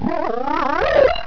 he_pain1.wav